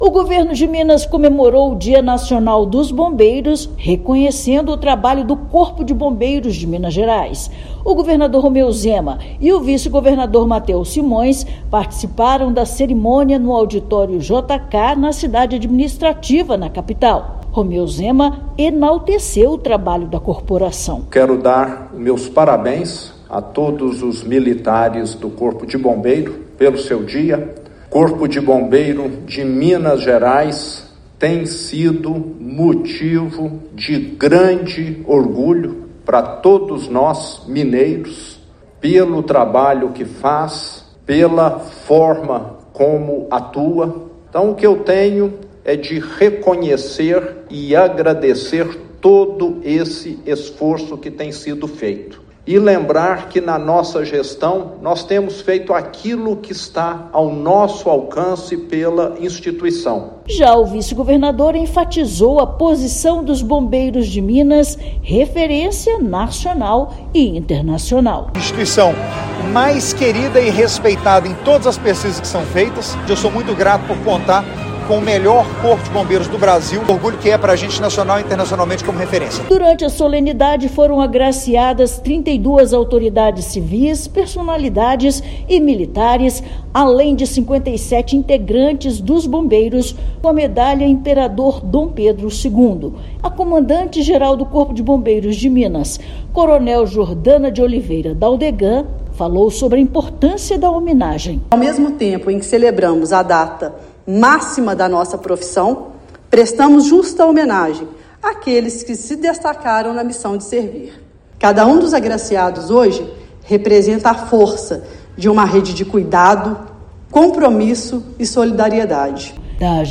Na cerimônia, 89 autoridades, personalidades e militares foram agraciados com a entrega da Medalha da Ordem ao Mérito Imperador Dom Pedro II. Ouça matéria de rádio.